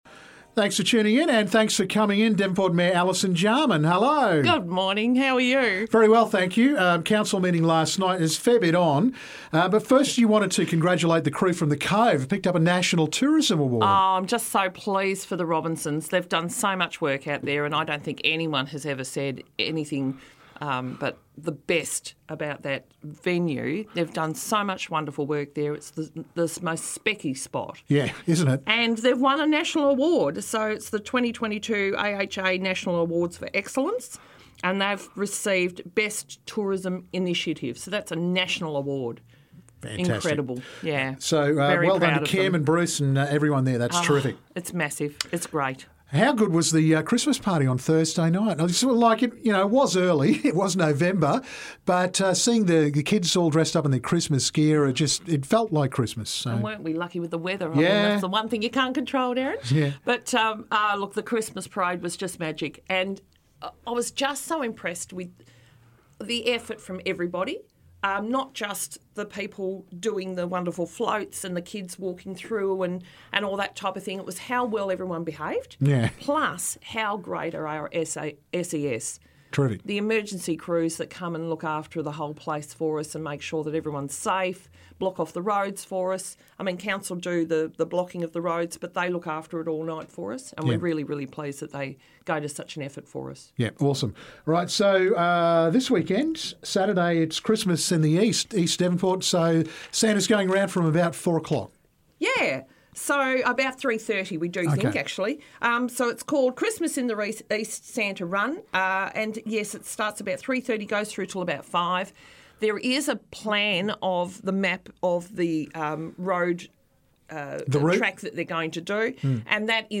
Work on William St is finished. Devonport Mayor Alison Jarman also talks about Waterfront Park, E Scooters and Christmas events.